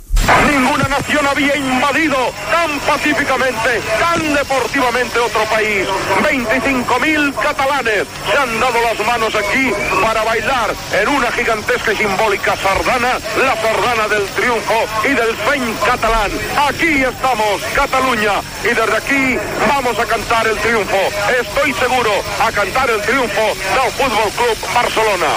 Transmissió de la final de la Recopa d' Europa de futbol entre el FC Barcelona i el Fortuna de Düsseldorf al Saint Jakob Stadium, de Basilea (Suïssa). Comentari sobre l'ambient al camp abans del partit
Esportiu